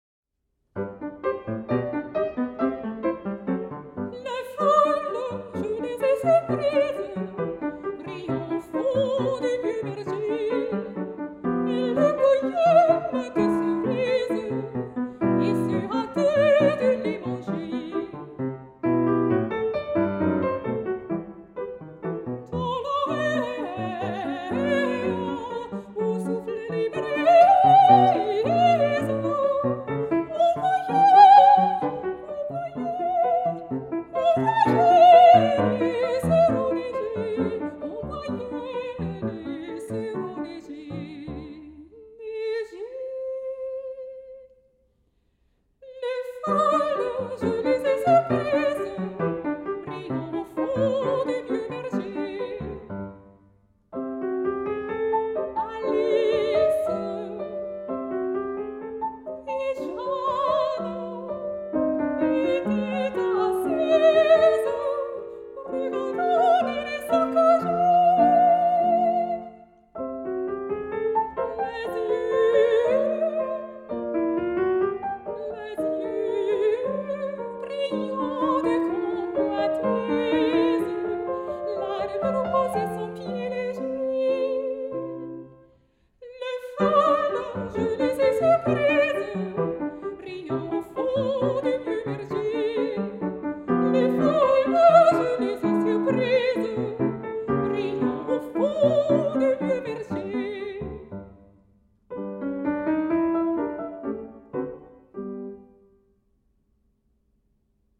For Voice and Piano, Text by Lucien Dhuguet